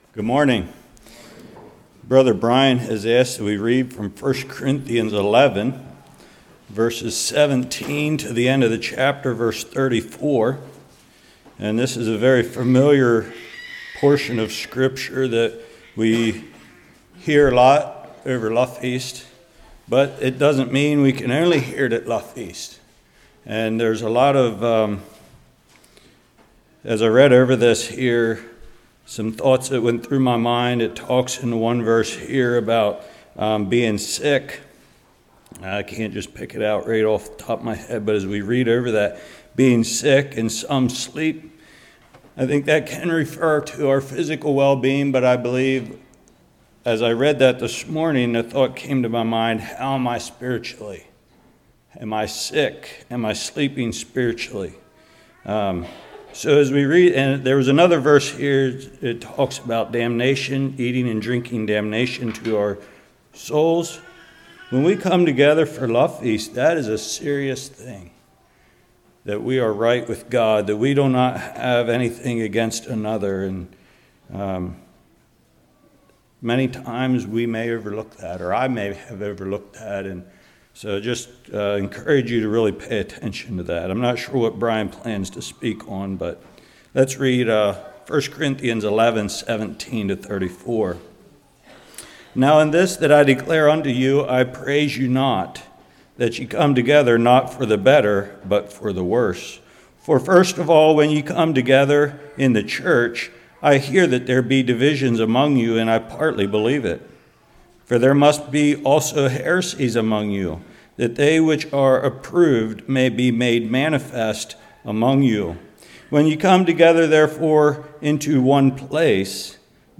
1 Corinthians 11:17-34 Service Type: Morning A brief history of the annual visit Why do we do it?